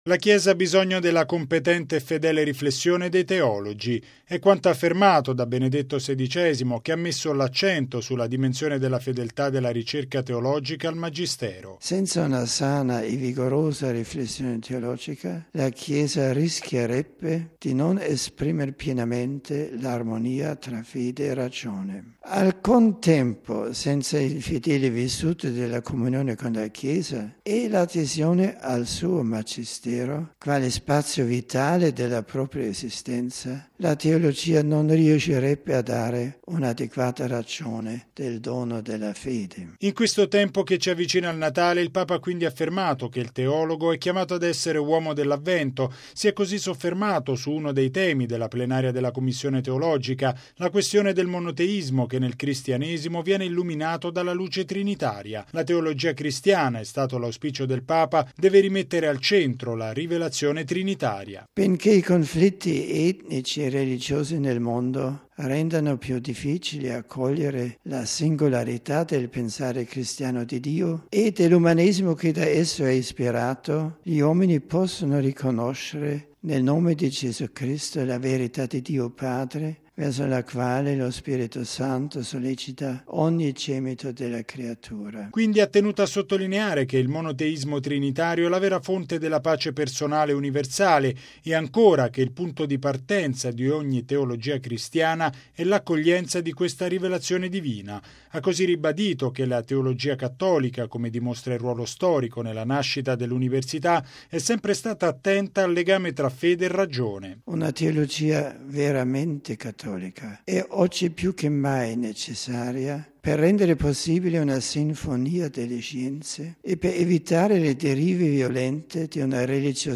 Logo 50Radiogiornale Radio Vaticana